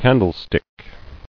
[can·dle·stick]